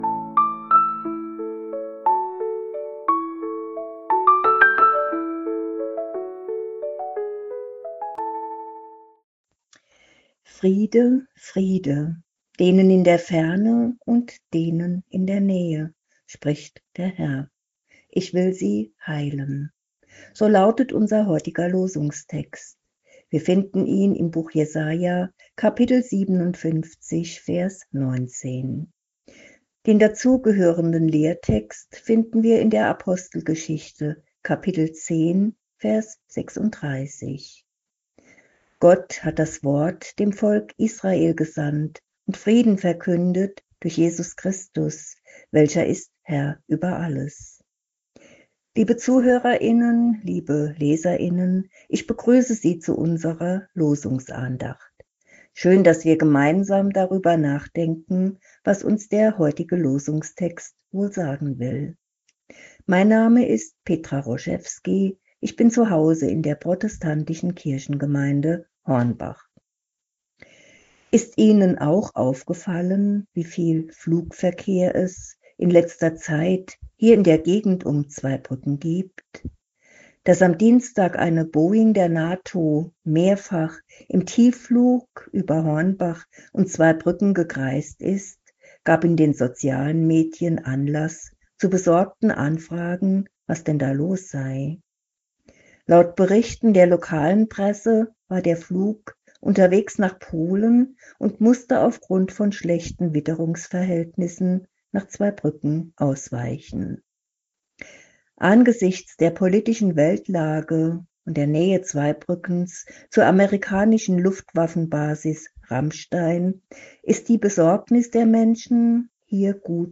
Losungsandacht für Montag, 21.07.2025